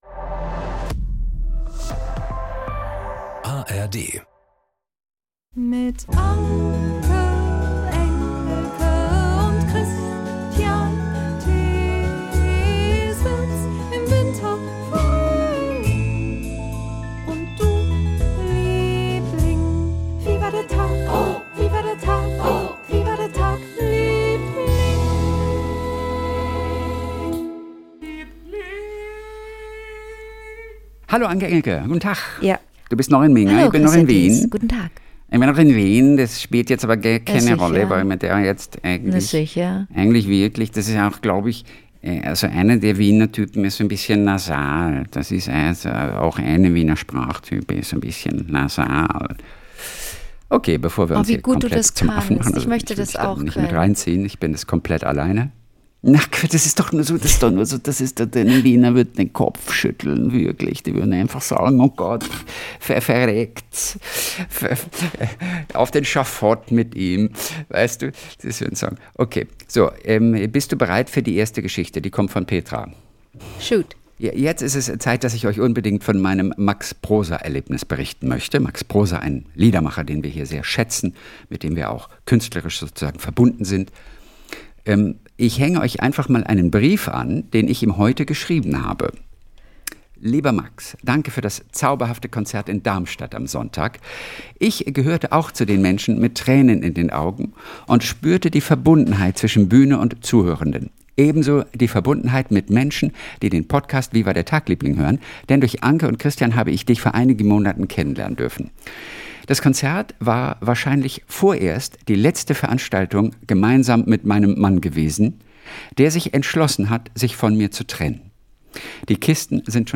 Jeden Montag und Donnerstag Kult: SWR3-Moderator Kristian Thees ruft seine beste Freundin Anke Engelke an und die beiden erzählen sich gegenseitig ihre kleinen Geschichtchen des Tages.